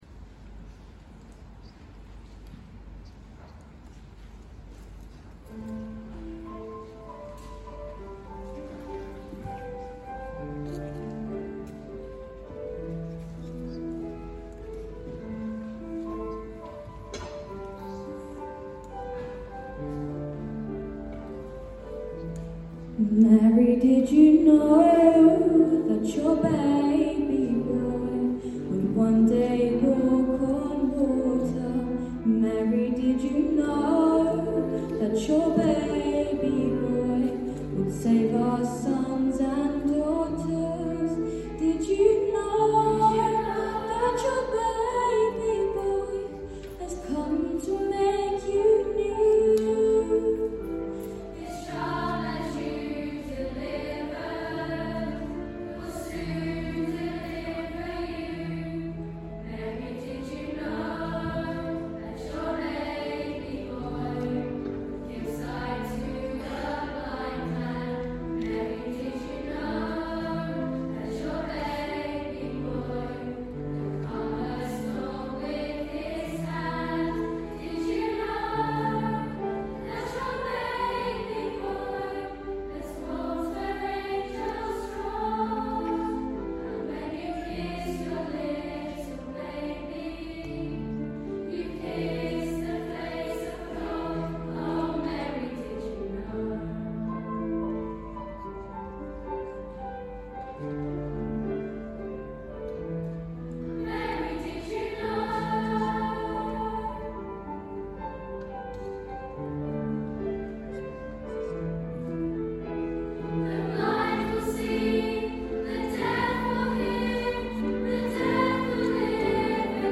Mary Did You Know? | Secondary Choir